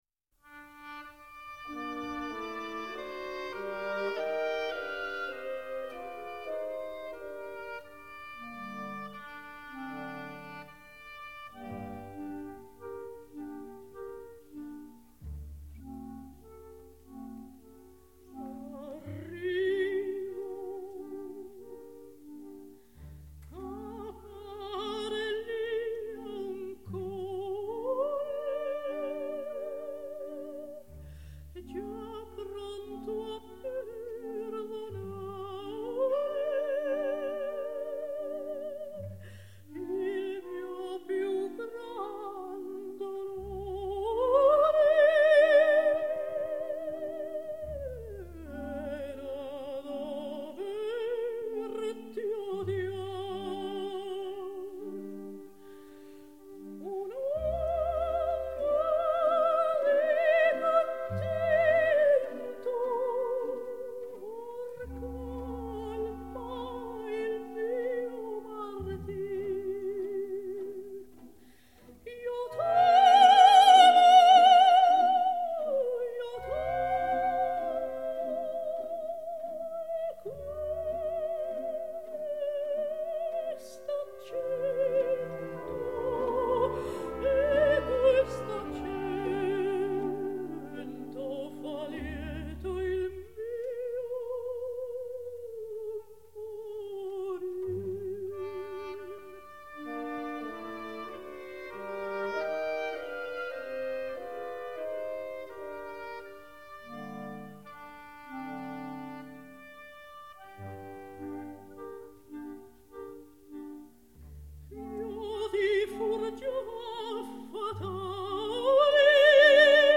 l’ària del 4at acte
reple d’aquells pianíssims de so màgic